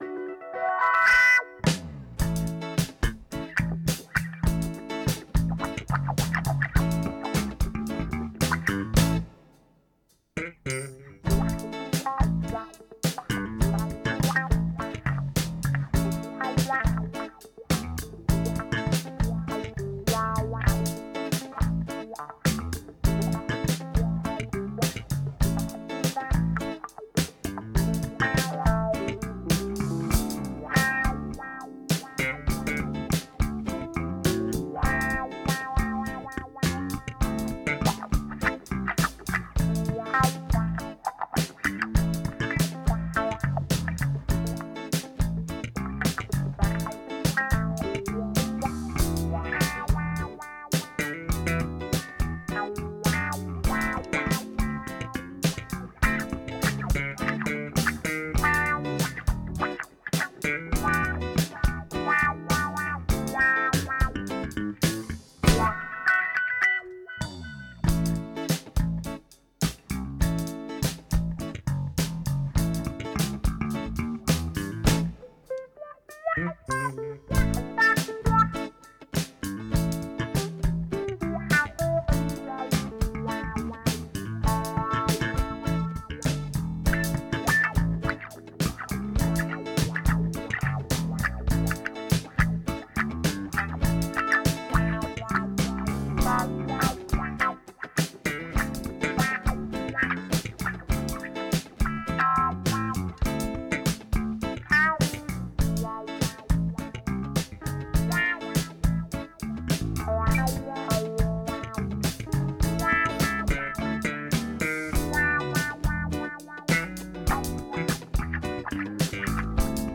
🏠 Accueil Repetitions Records_2025_09_01